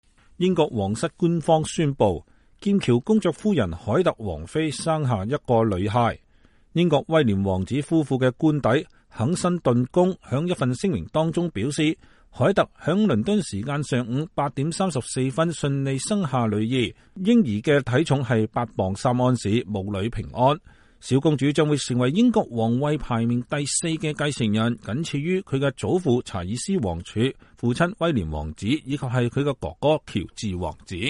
英國皇室人員在倫敦聖瑪麗醫院外向媒體宣布劍橋公爵夫人凱特王妃生了一個女孩。